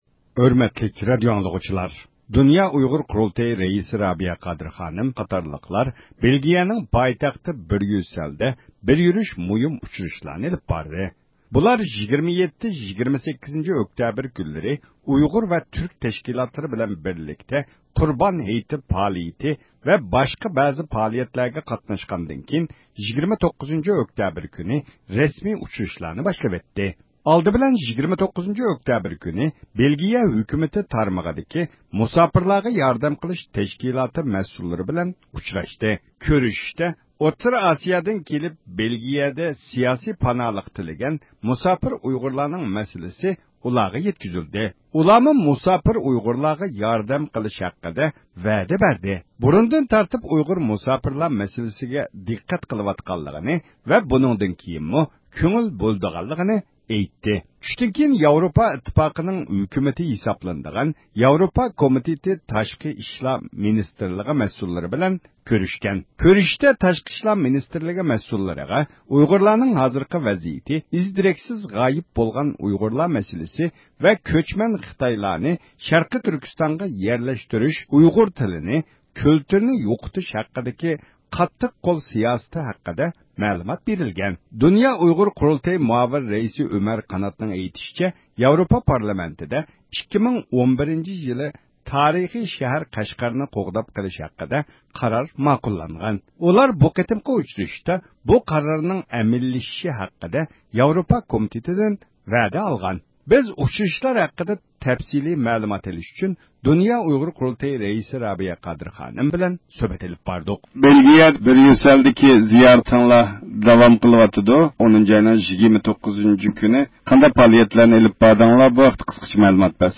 بىز بۇ ئۇچرىشىشلار ھەققىدە تەپسىلىي مەلۇمات ئېلىش ئۈچۈن دۇنيا ئۇيغۇر قۇرۇلتىيى رەئىسى رابىيە قادىر خانىم قاتارلىقلار بىلەن سۆھبەت ئېلىپ باردۇق.